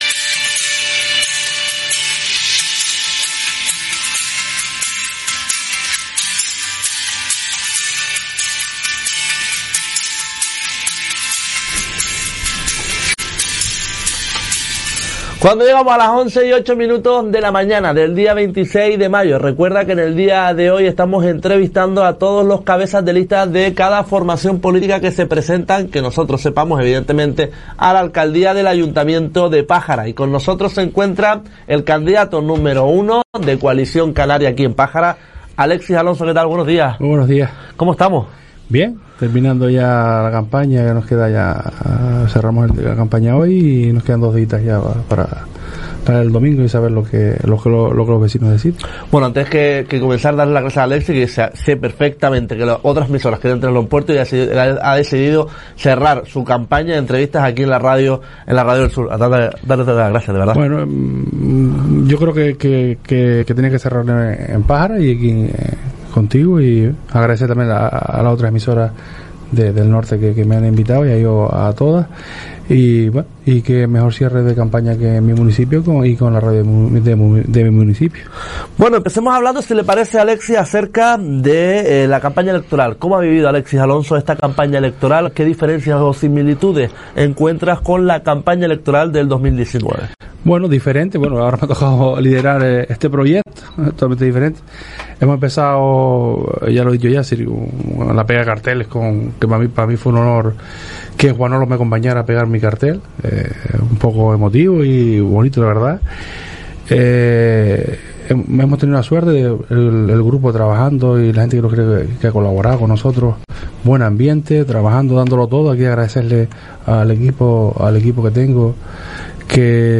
El Sur Despierta Entrevista